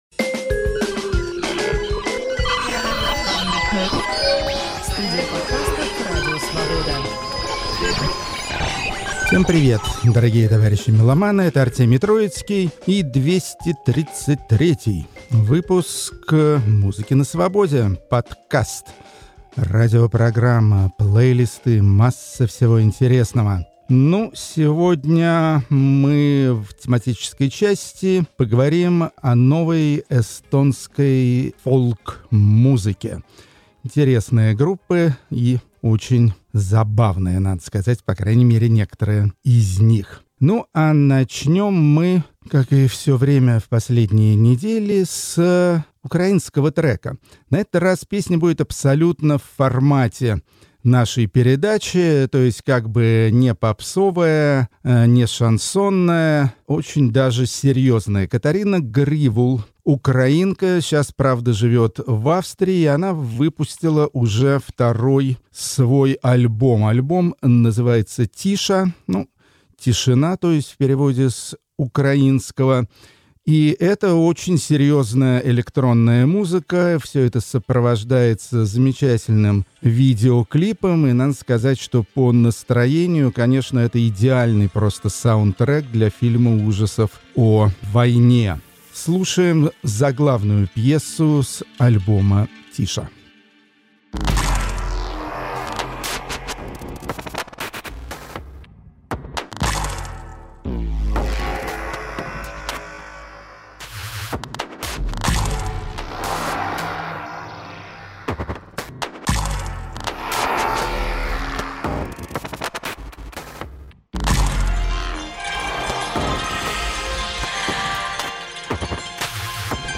Музыка для Эстонии - больше, чем музыка, считает Артемий Троицкий. И доказывает это своей обширной практикой рок-критика: чистый эстонский фолк, фолк-рок, чистую эстонскую фолк-тронику, а также прочие эстонские околоэтнические напевы и наигрыши, и всё это чисто по-эстонски неторопливое.